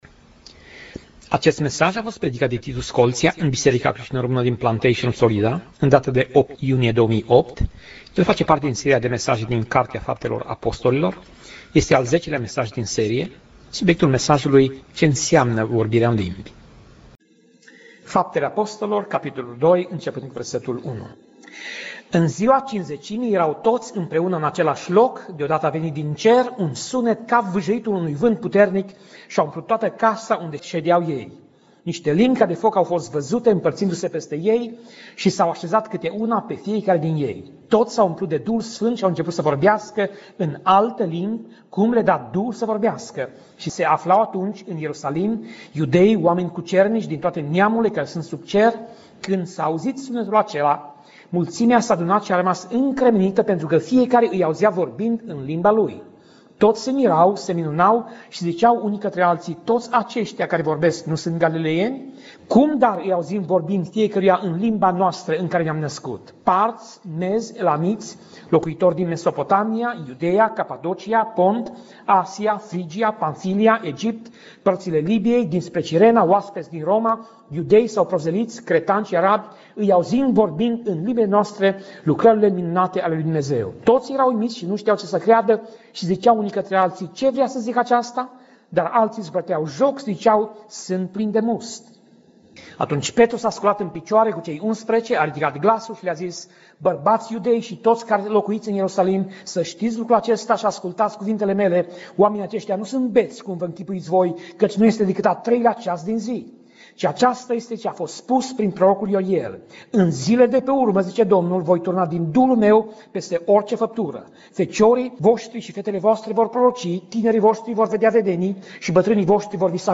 Pasaj Biblie: Faptele Apostolilor 2:1 - Faptele Apostolilor 2:21 Tip Mesaj: Predica